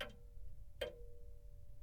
Grandfather_Clock_Sound_files